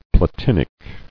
[pla·tin·ic]